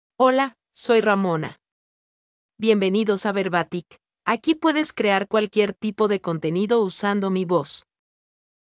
FemaleSpanish (Dominican Republic)
Ramona is a female AI voice for Spanish (Dominican Republic).
Voice sample
Female
Ramona delivers clear pronunciation with authentic Dominican Republic Spanish intonation, making your content sound professionally produced.